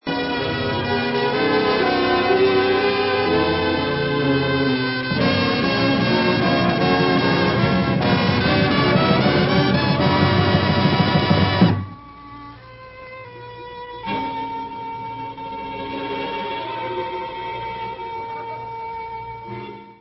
sledovat novinky v oddělení Jazz